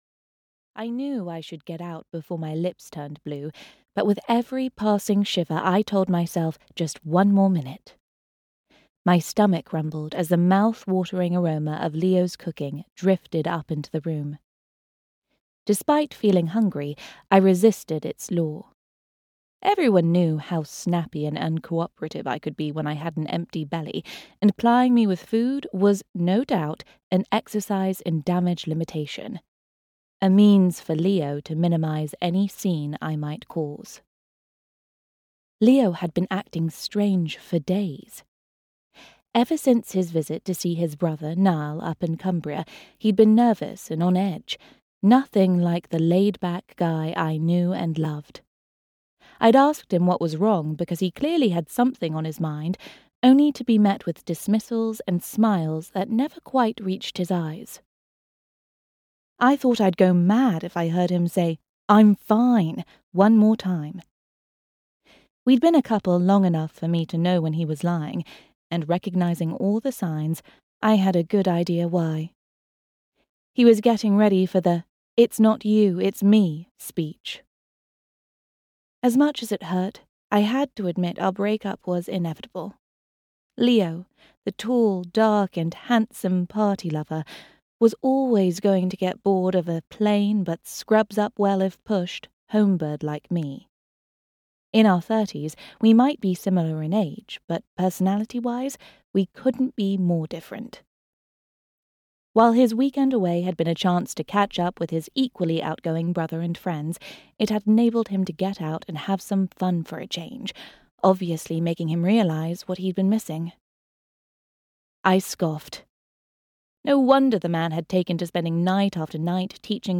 Tessa Cavendish is Getting Married (EN) audiokniha
Ukázka z knihy